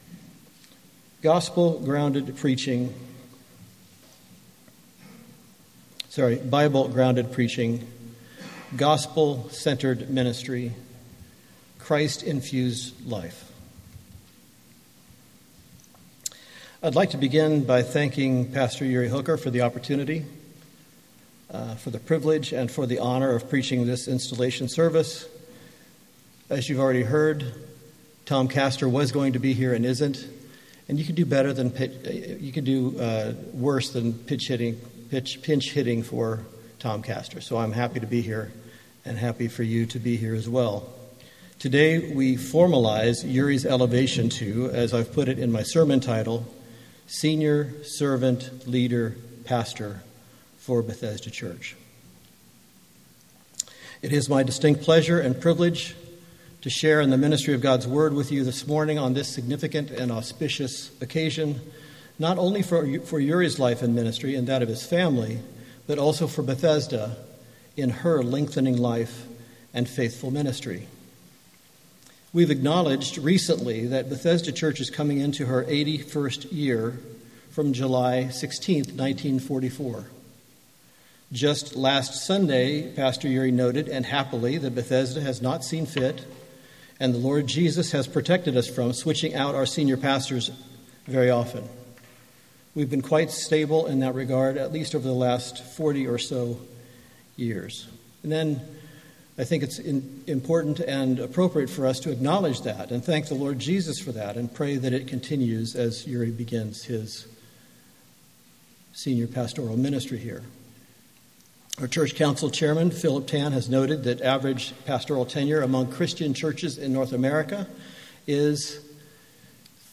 MP3 File Size: 32.6 MB Listen to Sermon: Download/Play Sermon MP3